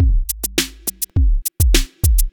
103 BPM Beat Loops Download